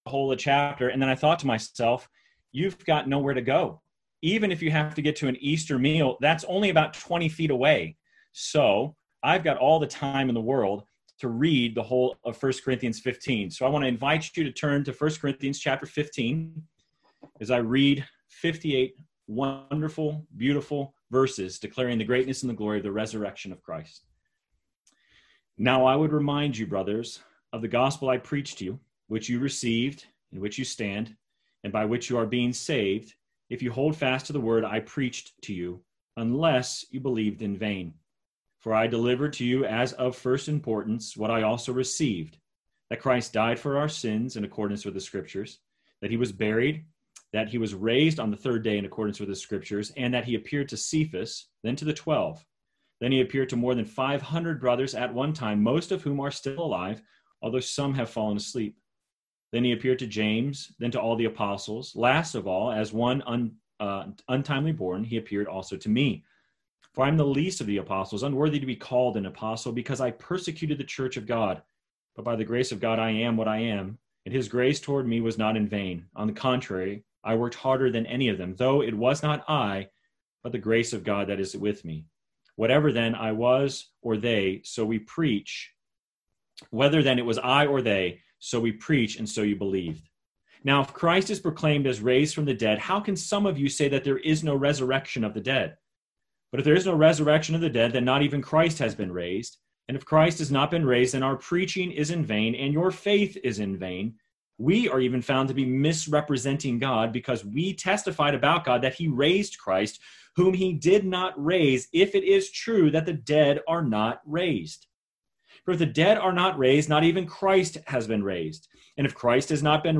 Sermon Text: 1 Corinthians 15 First Reading: Psalm 16 Second Reading: Luke 24:1-49